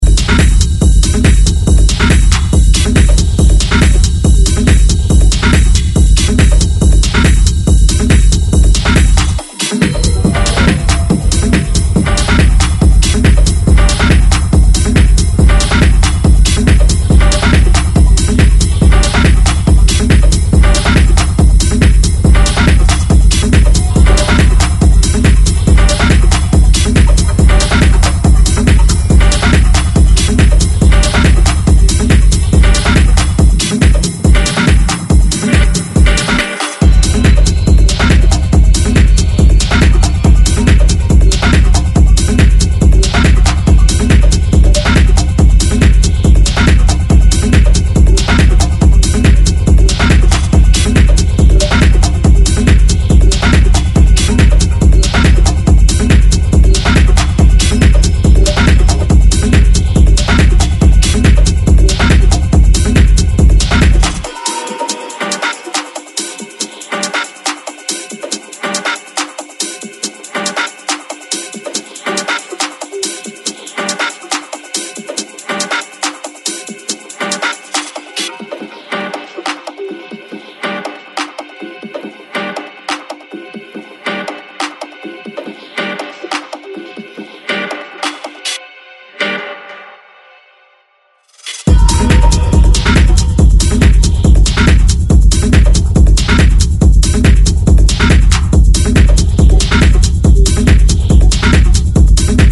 Electronic
Techno